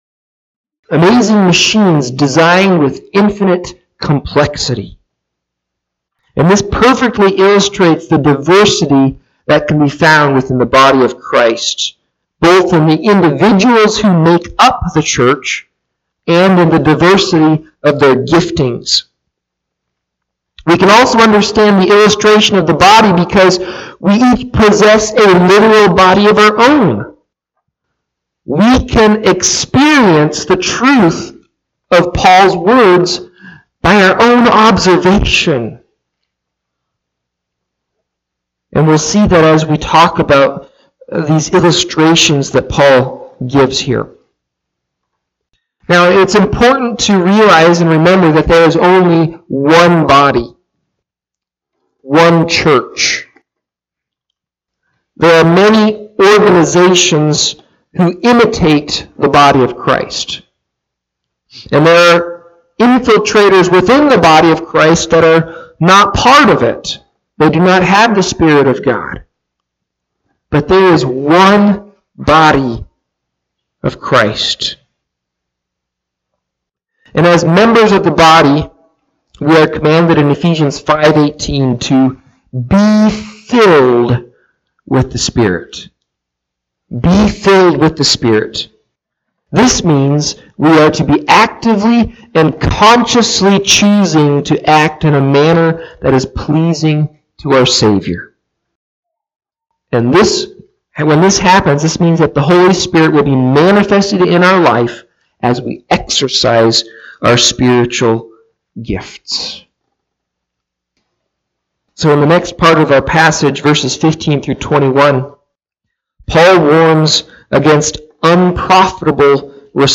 Listen to Audio of the sermon or Click Facebook live link above.
Service Type: Morning Sevice